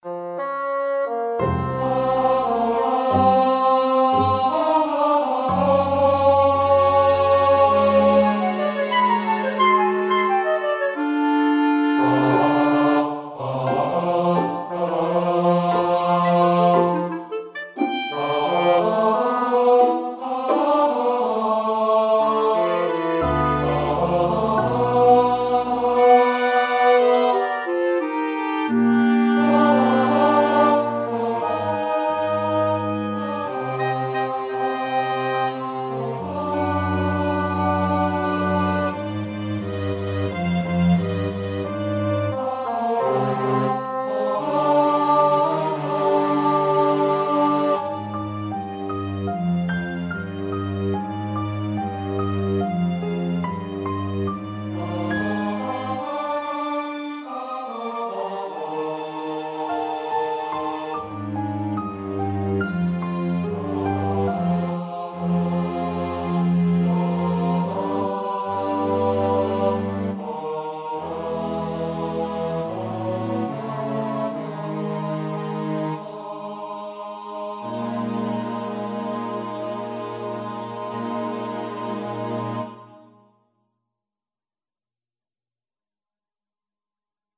Speaking and Singing (Baritone)
SATB Chorus - Singing